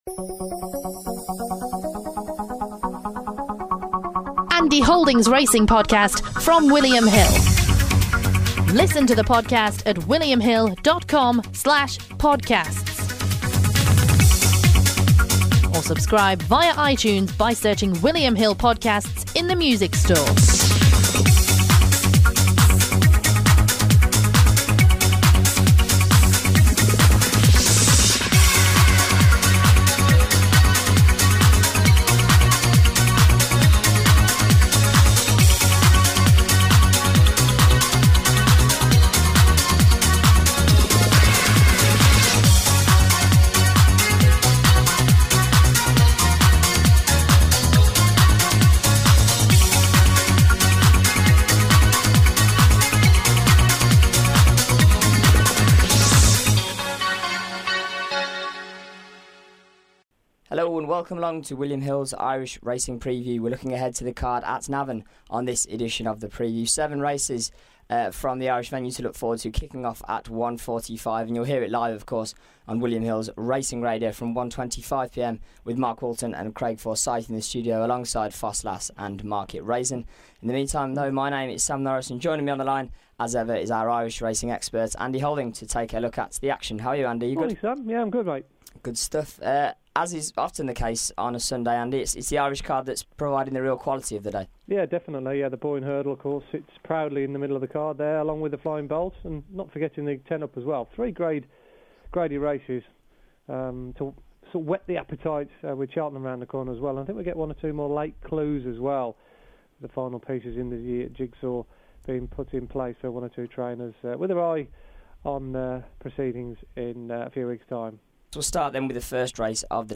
on the line to look ahead to all seven races on the card before offering his best bets.